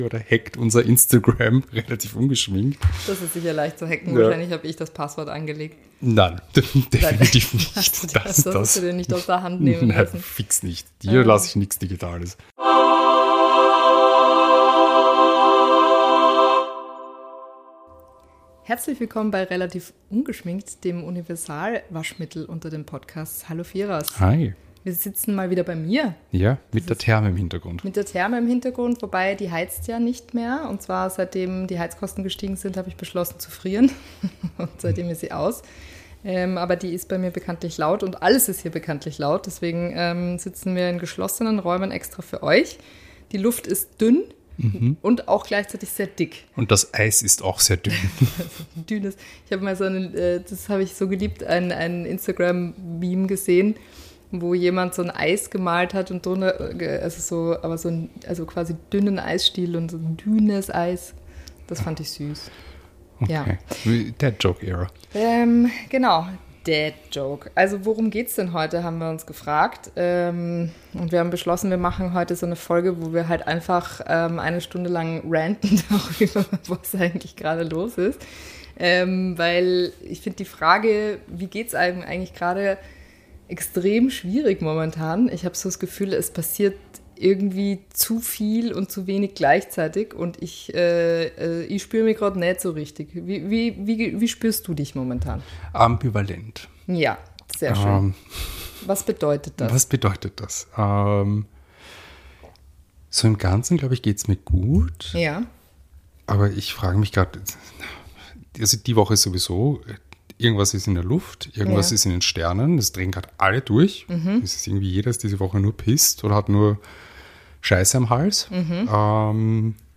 Heute launig.